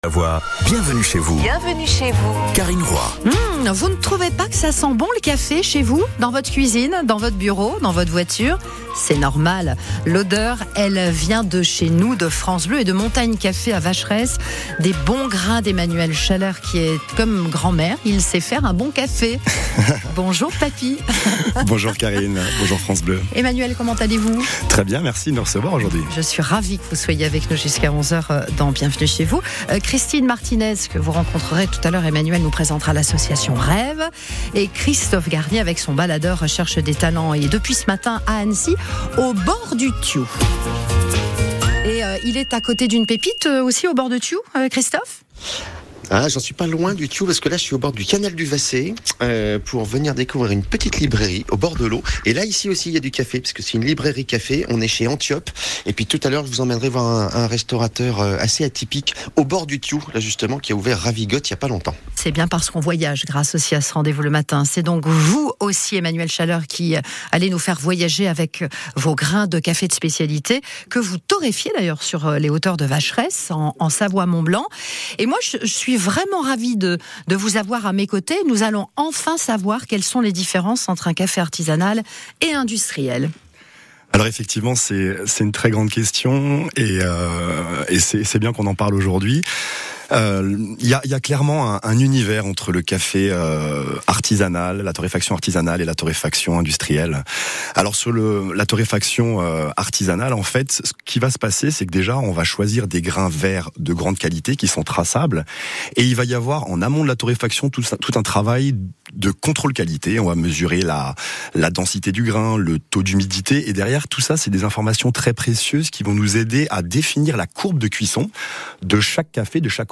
Emission France Bleu Pays de Savoie"Bienvenue chez vous